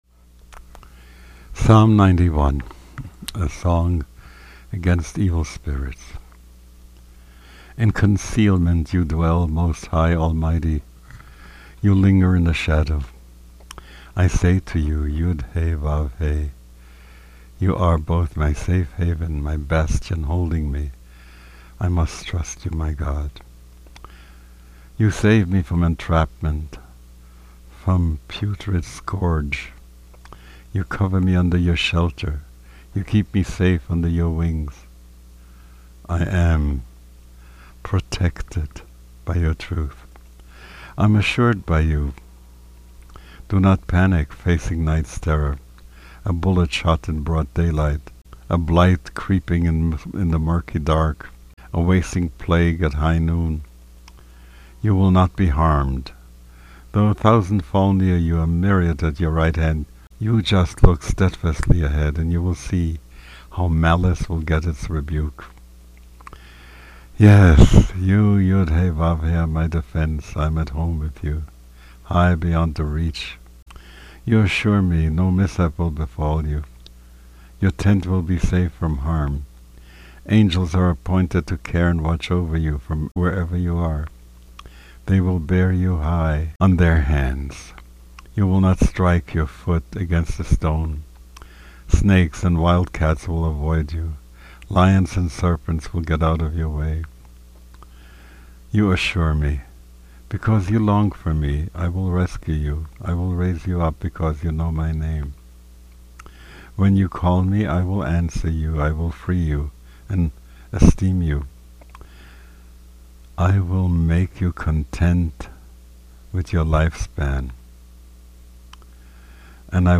These recordings were not done in the studio under perfect conditions with sophisticated microphones and complicated mixers.  They were made over Skype using a Skype recorder and edited using a simple audio editor.